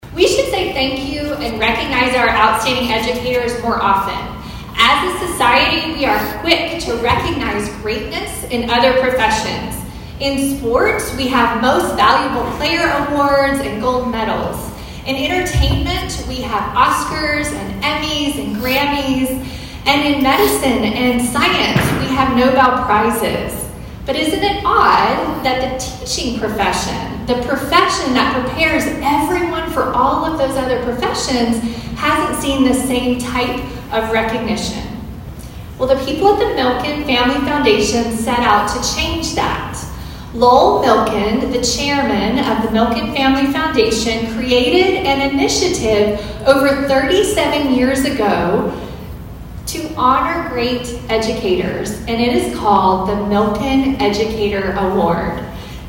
ABERDEEN, S.D.(HubCityRadio)- Students and faculty were in for a surprise of lifetime Thursday at Aberdeen Roncalli Middle & High School.